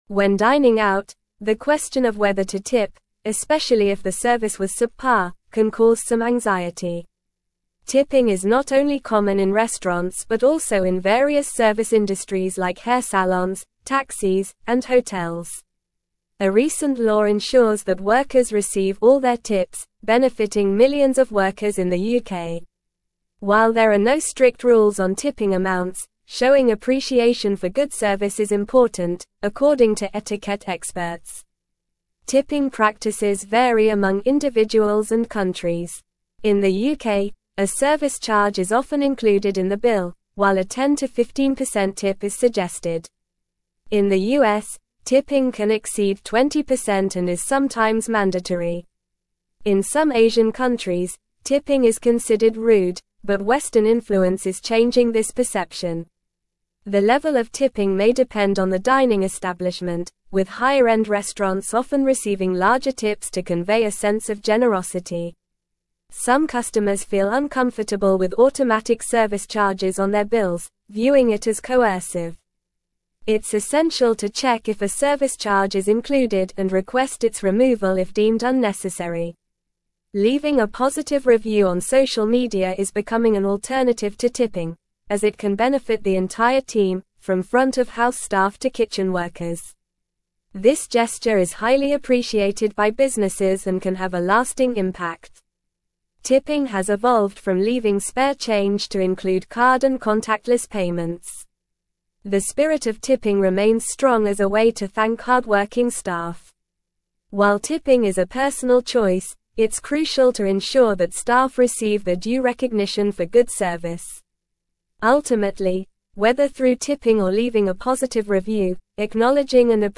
Normal
English-Newsroom-Advanced-NORMAL-Reading-Navigating-Tipping-Etiquette-Acknowledging-Good-Service-Graciously.mp3